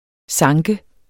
Udtale [ ˈsɑŋgə ]